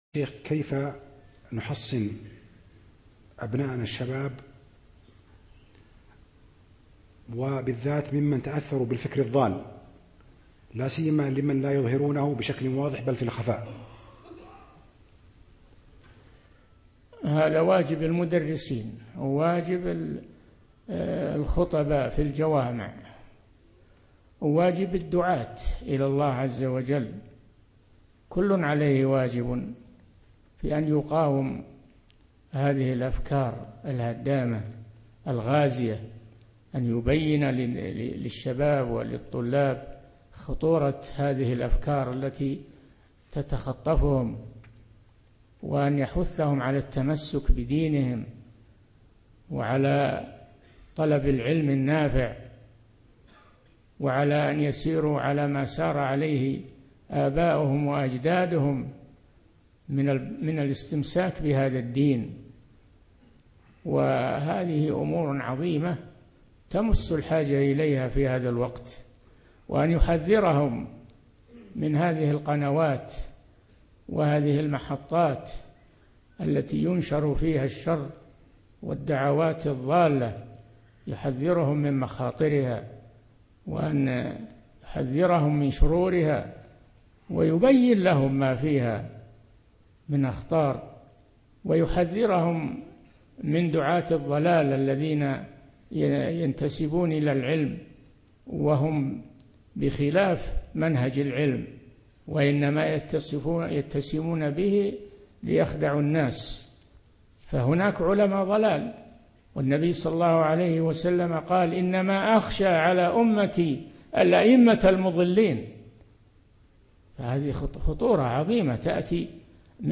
من مواعظ أهل العلم
Format: MP3 Mono 22kHz 32Kbps (VBR)